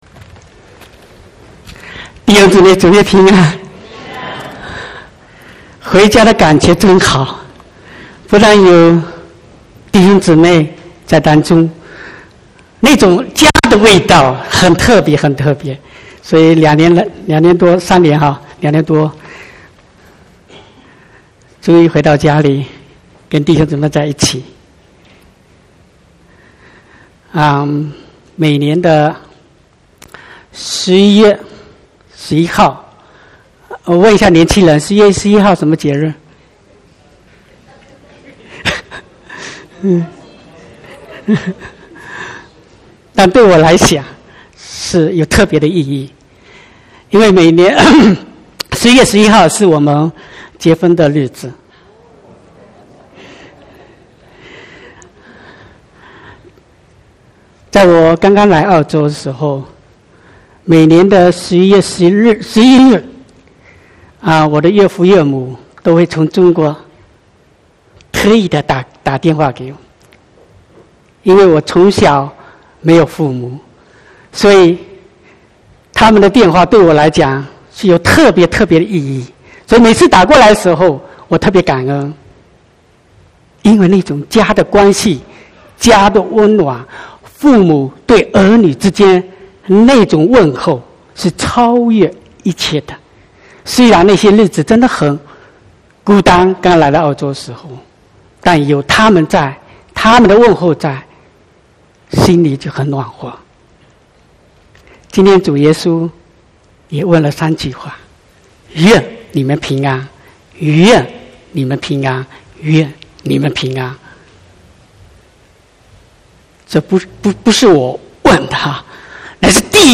25/11/2018 國語堂講道